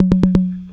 2 Foyer Roll.wav